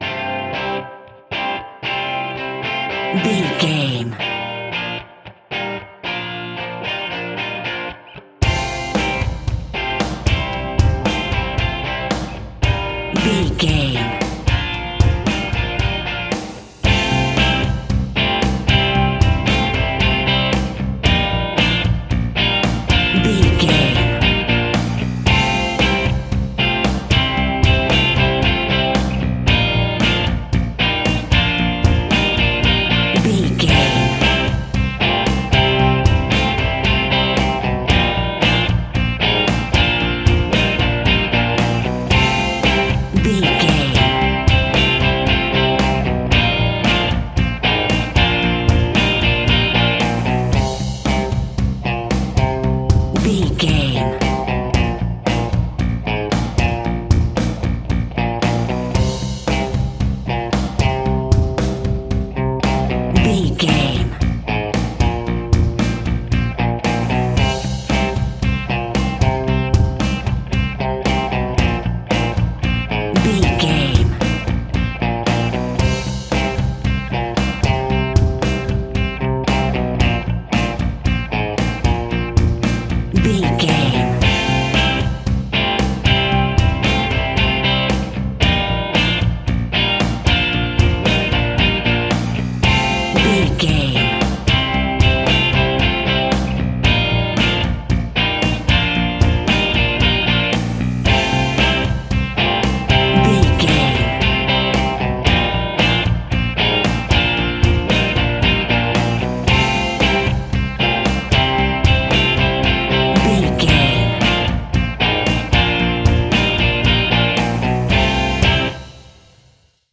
Ionian/Major
energetic
driving
heavy
electric guitar
bass guitar
drums
hard rock
distortion
instrumentals
distorted guitars
hammond organ